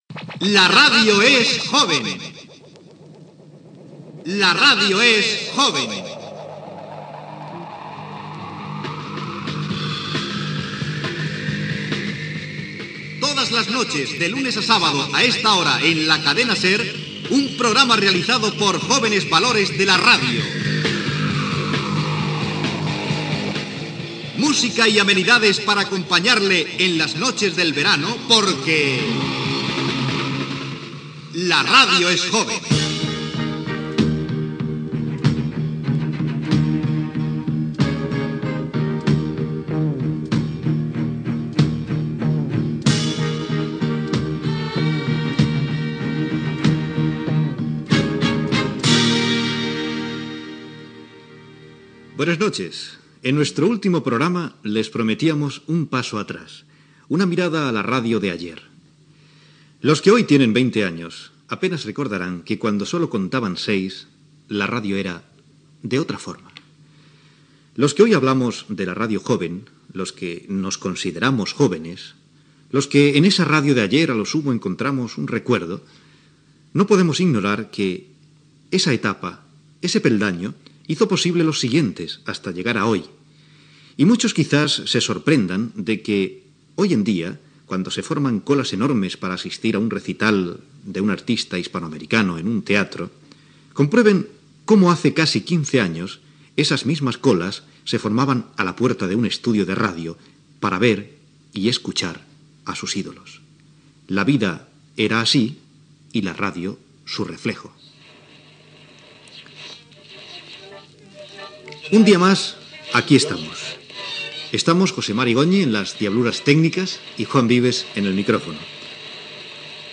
Careta i presentació del programa.
Musical